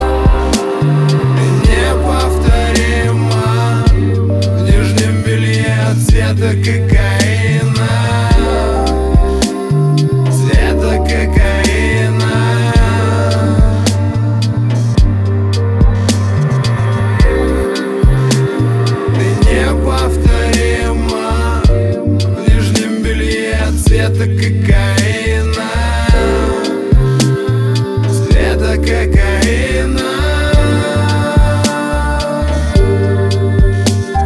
• Качество: 320, Stereo
лирика
русский рэп